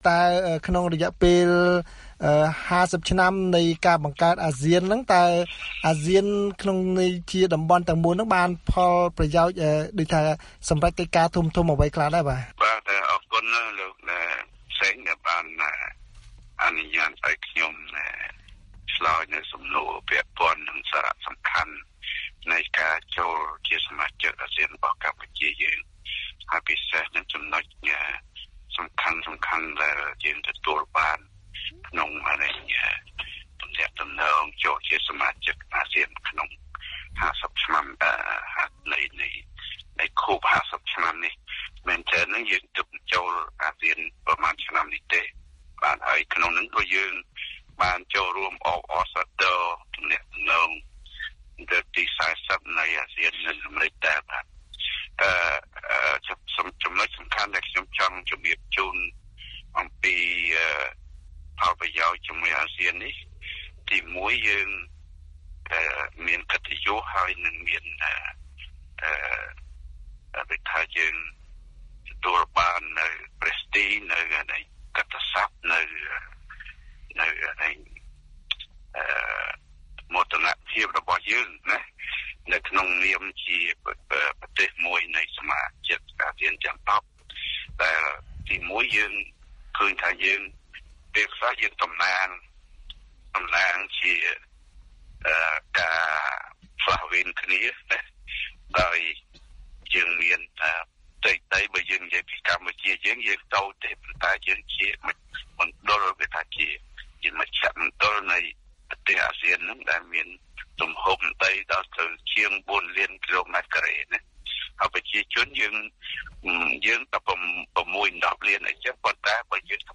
បទសម្ភាសន៍ VOA៖ កម្ពុជាការពារសាមគ្គីភាពអាស៊ានទោះបីមានបញ្ហាសមុទ្រចិនខាងត្បូង